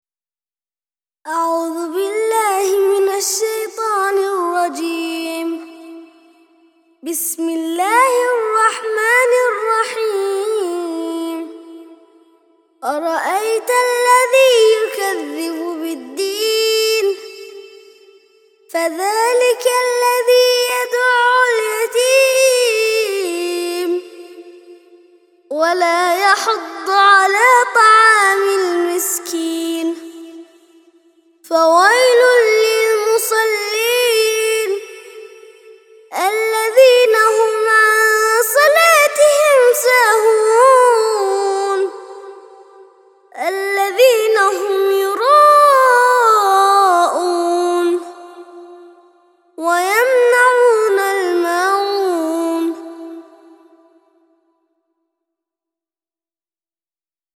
107- سورة الماعون - ترتيل سورة الماعون للأطفال لحفظ الملف في مجلد خاص اضغط بالزر الأيمن هنا ثم اختر (حفظ الهدف باسم - Save Target As) واختر المكان المناسب